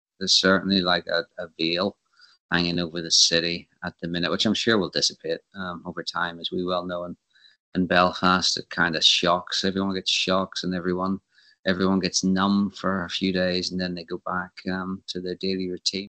Belfast man living in New Orleans says community is in shock